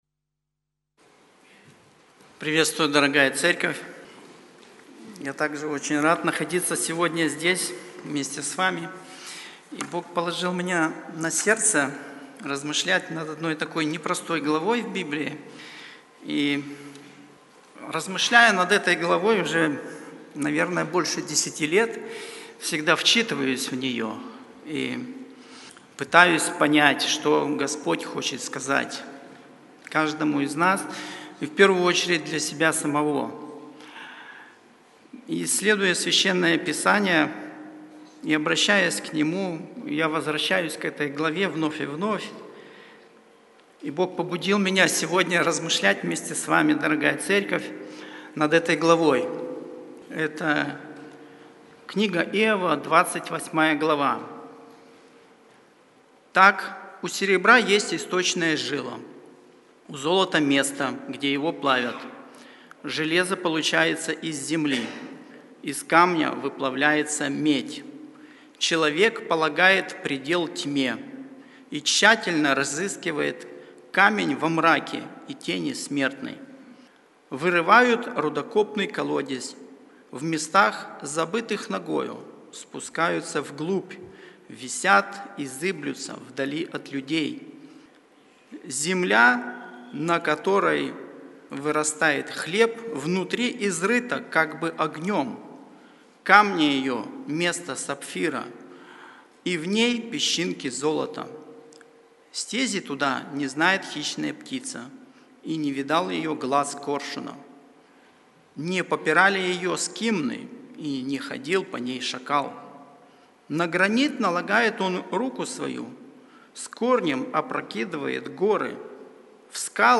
Церковь евангельских христиан баптистов в городе Слуцке